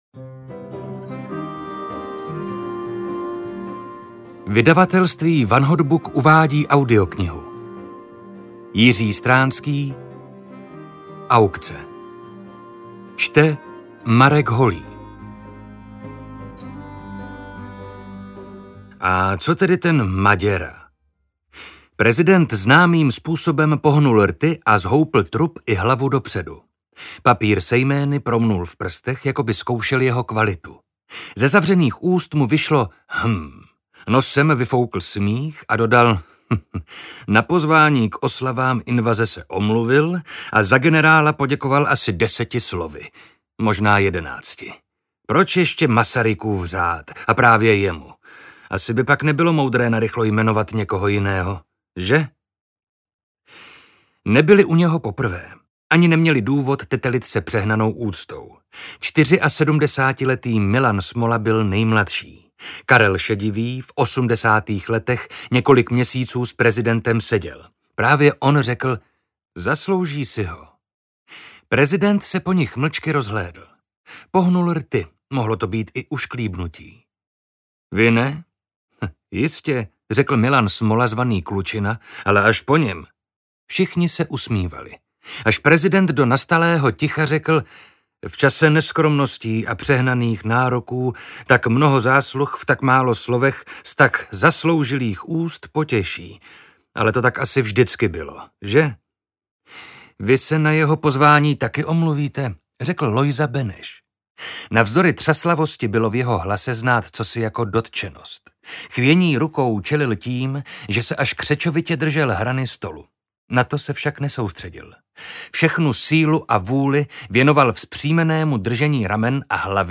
Aukce audiokniha
Ukázka z knihy